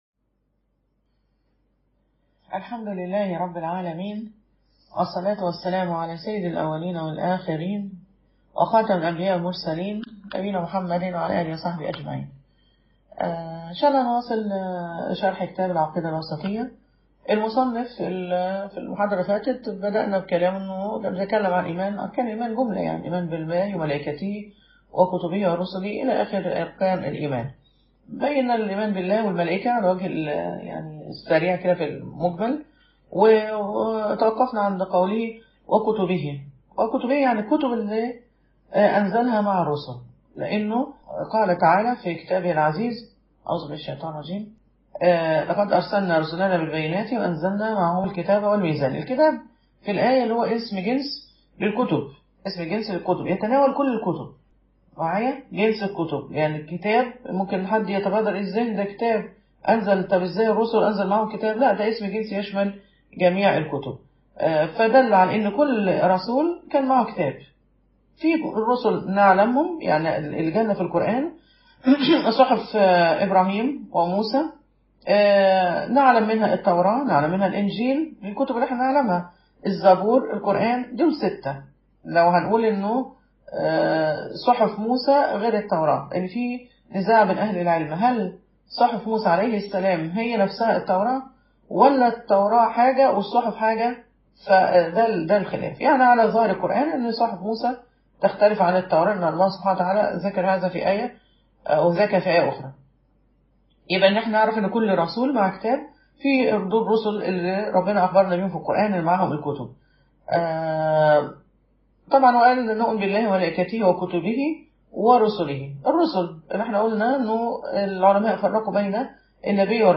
شرح العقيدة الواسطية_المحاضرة الرابعة